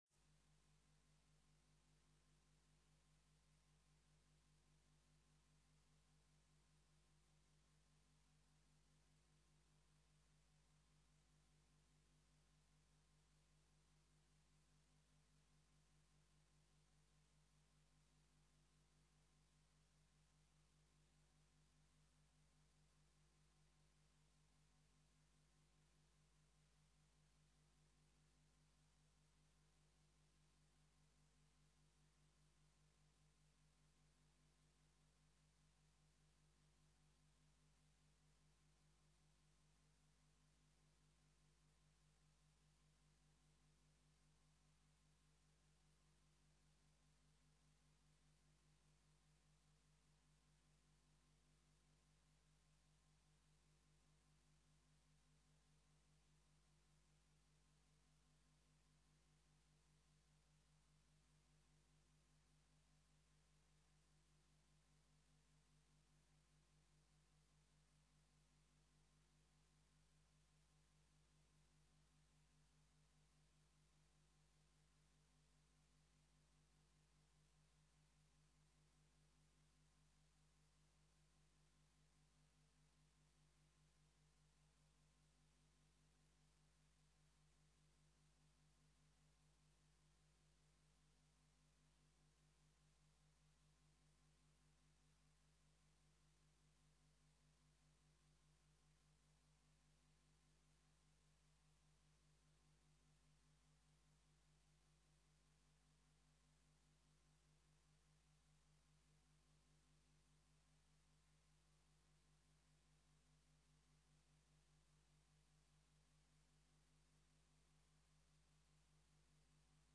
Informatiebijeenkomst 16 april 2013 20:00:00, Gemeente Tynaarlo
Datum: 16 apr. 2013, 20:00 Locatie: Raadszaal Opties bij deze vergadering Print agenda Download documenten Legenda Opslaan in uw agenda Agenda Hoofdvergadering Raadszaal 1. 1.